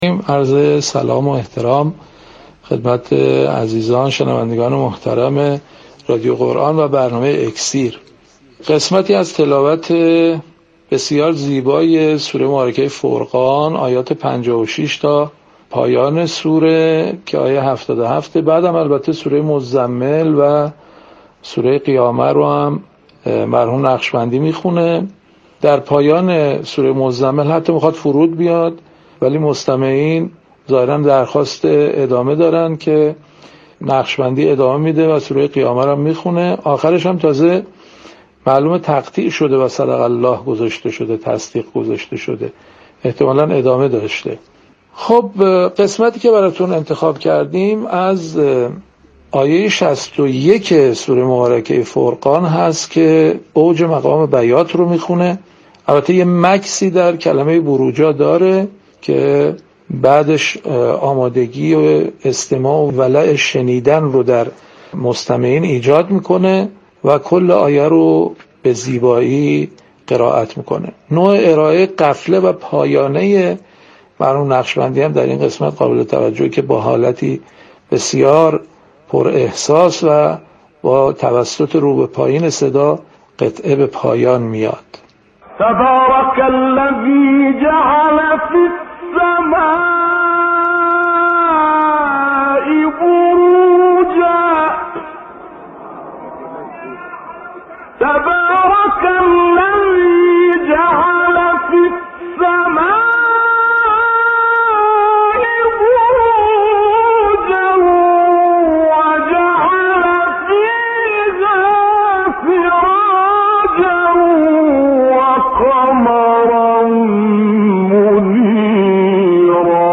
یادآور می‌شود، این تحلیل در برنامه «اکسیر» از شبکه رادیویی قرآن پخش شد.